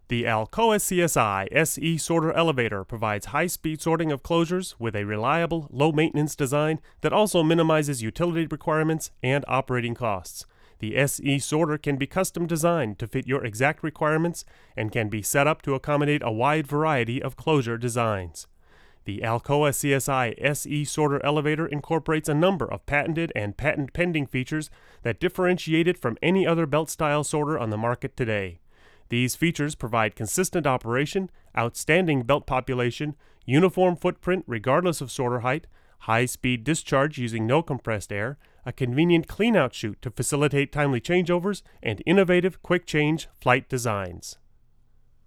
These are raw recordings - I've done nothing with EQ or compression on these files.
The Seb was set with the 15dB pad - no HF EQ or LF EQ.
Oh, and stick a pencil in front of the mic you end up using - there are plosives!